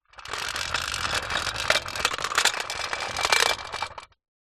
Звуки самоката
Шум колес самоката